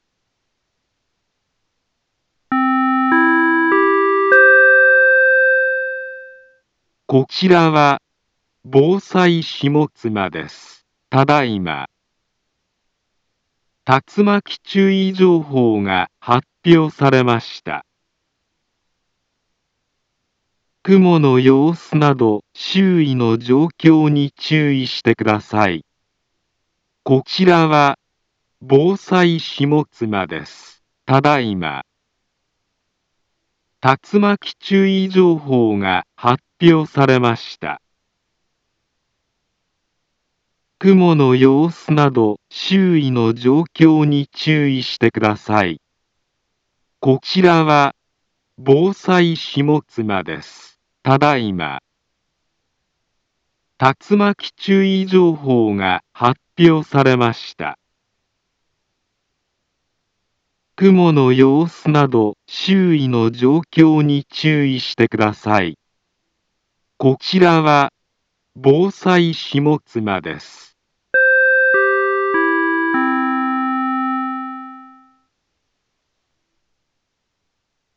Back Home Ｊアラート情報 音声放送 再生 災害情報 カテゴリ：J-ALERT 登録日時：2024-11-27 19:39:21 インフォメーション：茨城県北部、南部は、竜巻などの激しい突風が発生しやすい気象状況になっています。